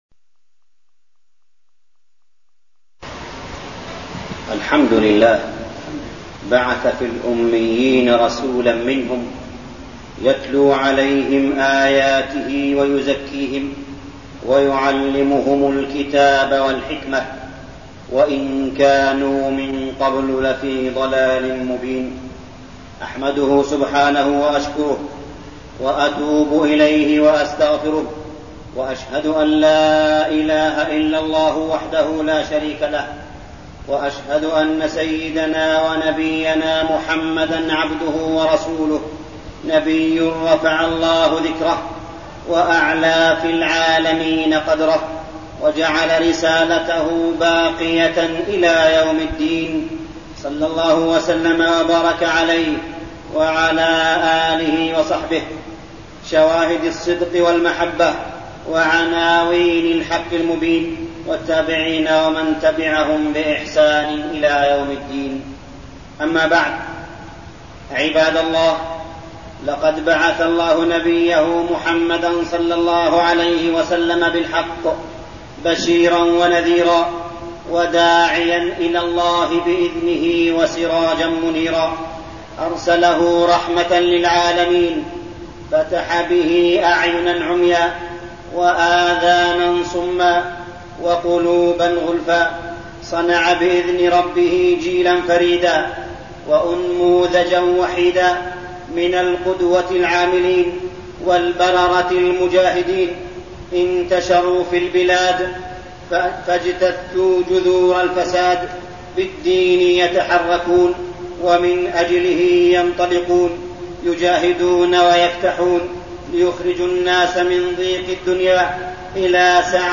تاريخ النشر ٢١ ربيع الأول ١٤١٠ هـ المكان: المسجد الحرام الشيخ: معالي الشيخ أ.د. صالح بن عبدالله بن حميد معالي الشيخ أ.د. صالح بن عبدالله بن حميد الأمن والإيمان